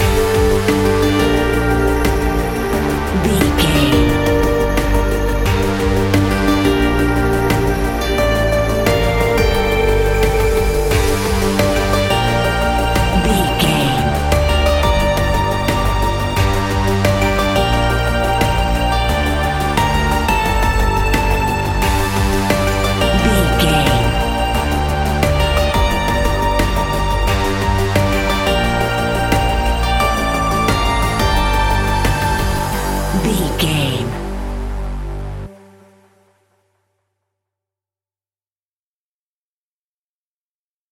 royalty free music
In-crescendo
Thriller
Aeolian/Minor
ominous
dark
suspense
haunting
eerie
synthesizer
drum machine
ticking
electronic music
Horror Synths